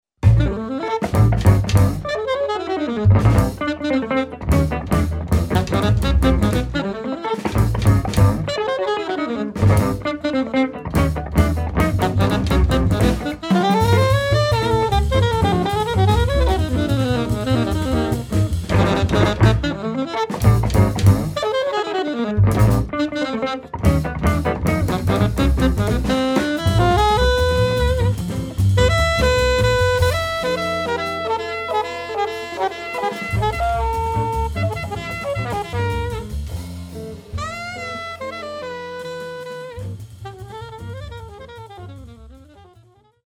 guitar
sax, flute
bass
drums